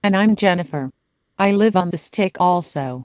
We also include 2 very high quality SAPI-5 compliant voices: RealSpeak